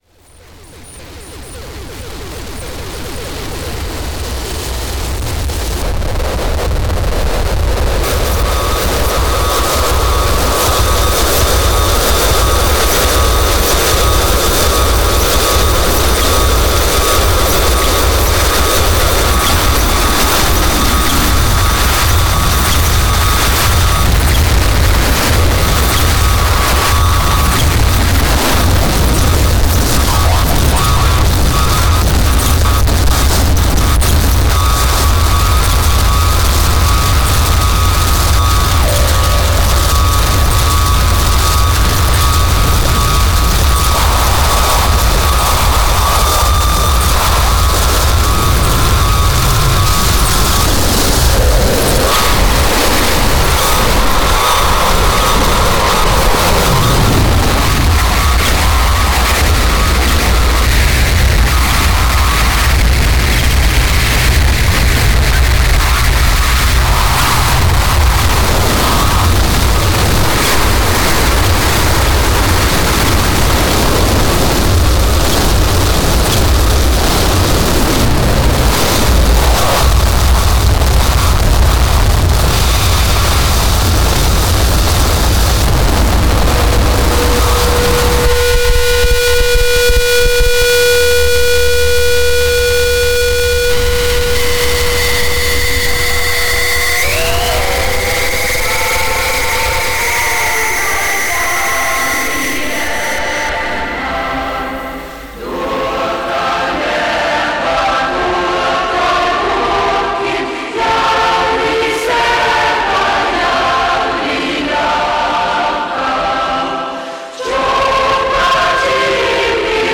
Sound Art non-music!
そのあとは強烈な金属打撃系インダスや激ハーシュなど文句なしのサウンドです。